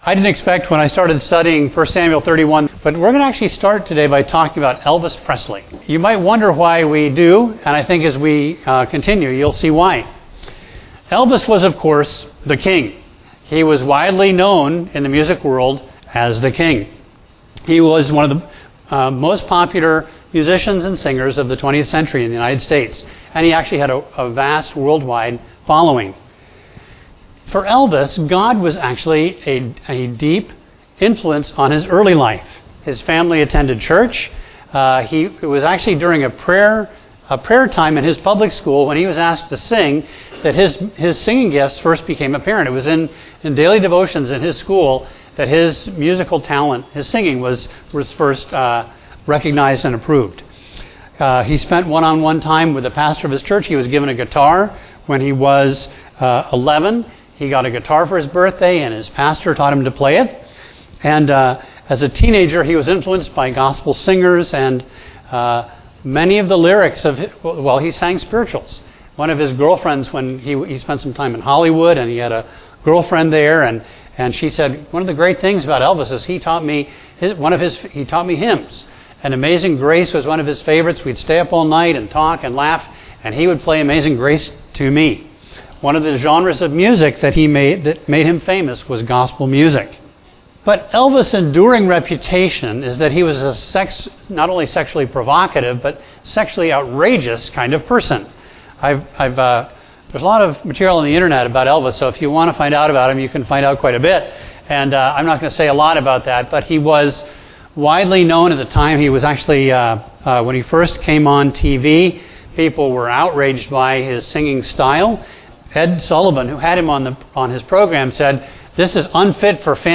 A message from the series "David."